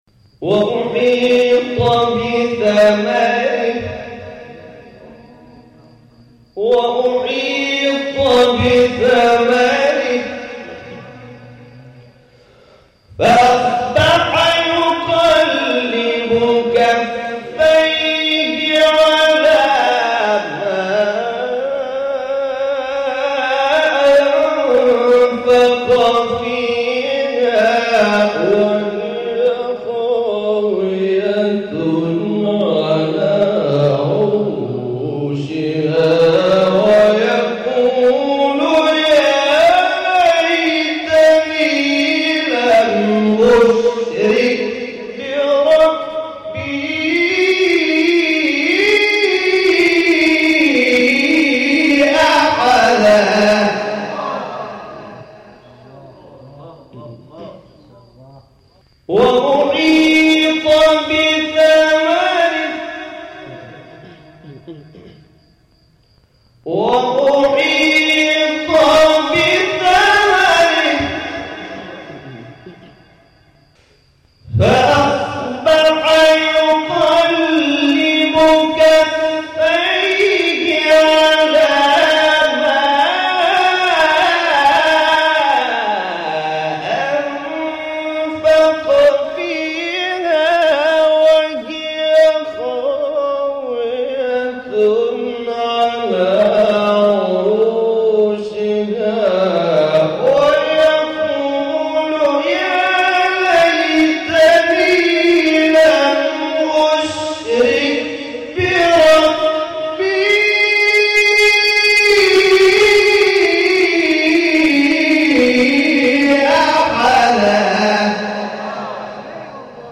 شبکه اجتماعی: فرازهای صوتی از تلاوت قاریان ممتاز کشور را می‌شنوید.
سوره کهف در مقام نهاوند